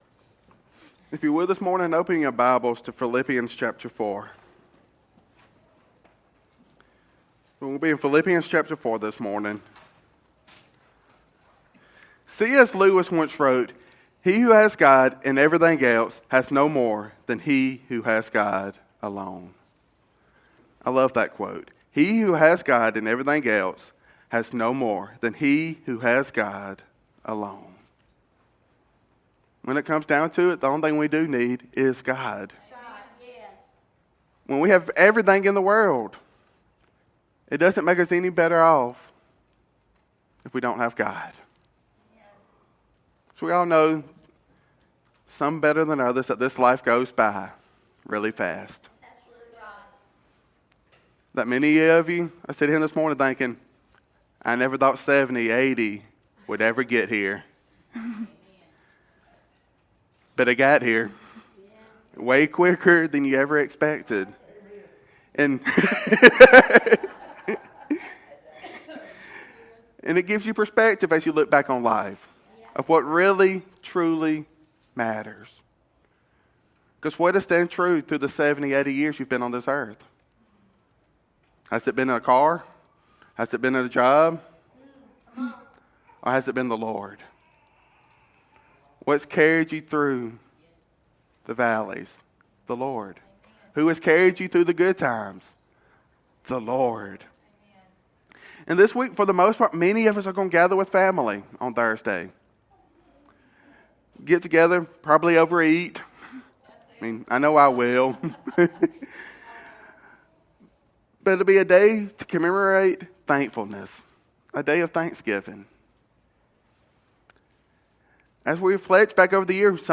Whether 2025 brought you abundance or deep tragedy, the Apostle Paul’s words—written from a dark prison cell—remind us that we can face any circumstance through the strength of Christ. This sermon provides a scriptural roadmap for shifting our focus from our temporary "dash" on earth to the eternal promises of God.